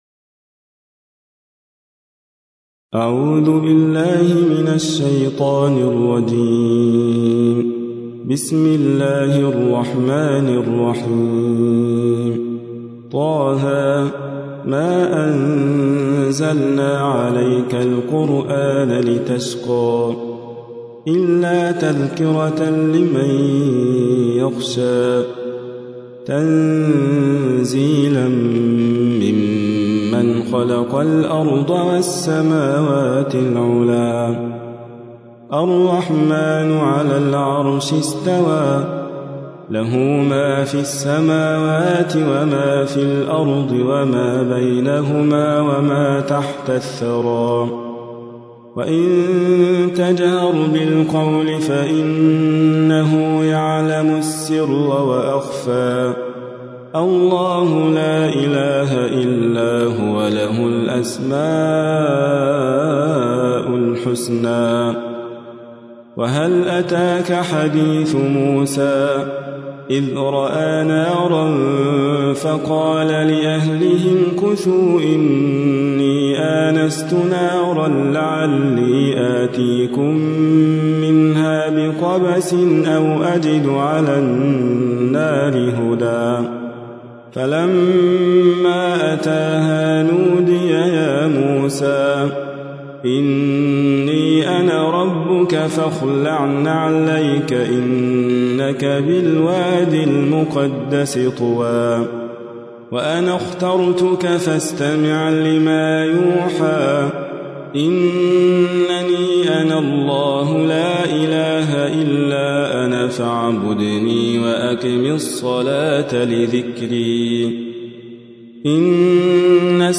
تحميل : 20. سورة طه / القارئ حاتم فريد الواعر / القرآن الكريم / موقع يا حسين